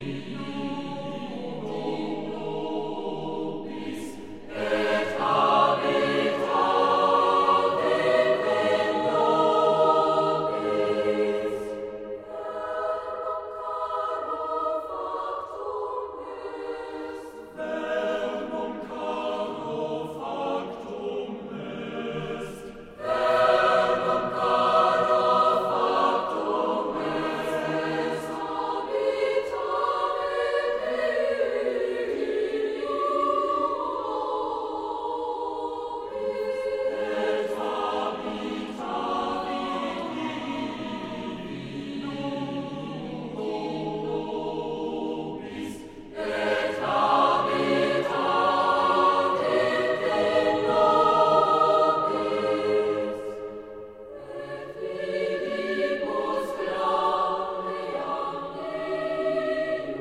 • Sachgebiet: Klassik: Chormusik